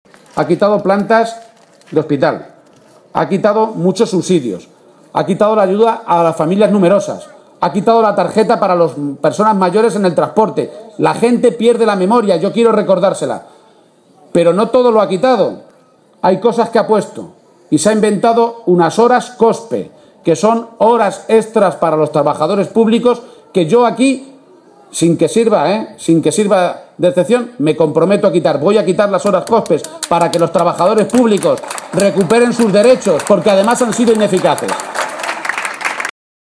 Participa en Bargas (Toledo), en un acto público junto con la lista socialista que se presenta para revalidar la mayoría municipal